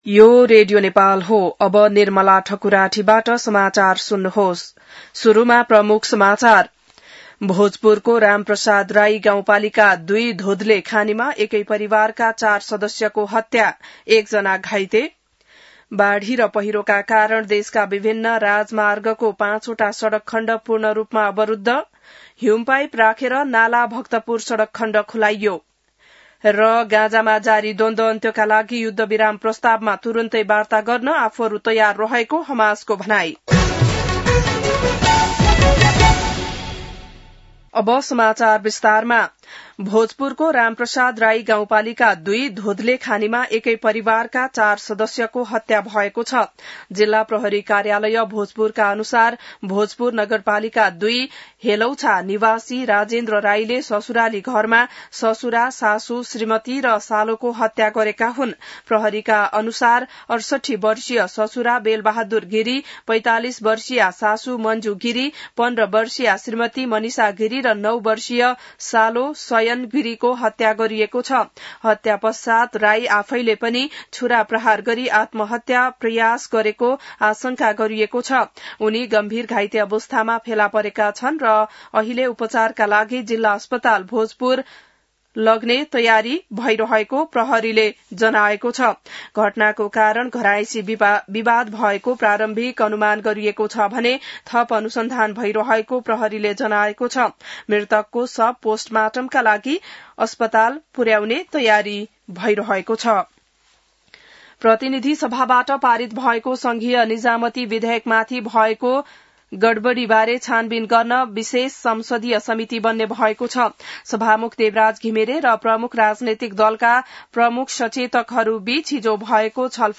बिहान ९ बजेको नेपाली समाचार : २२ असार , २०८२